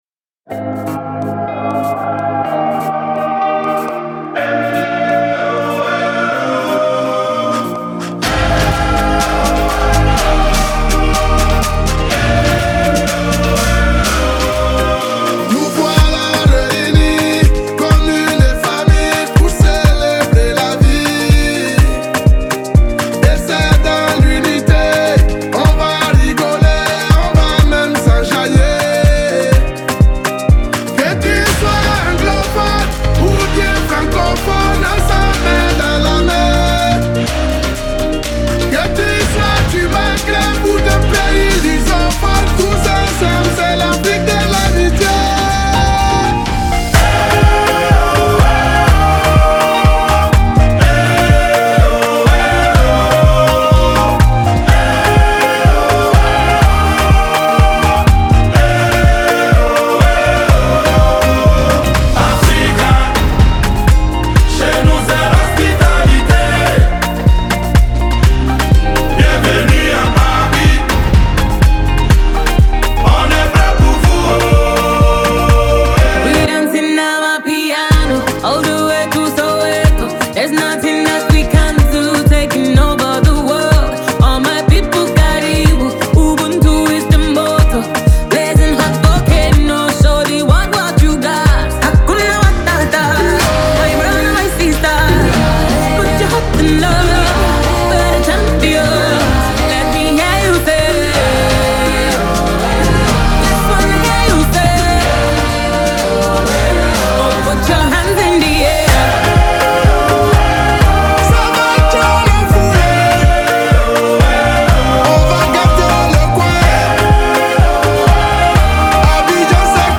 An explosion of rhythms and melodies
infectious beats and uplifting lyrics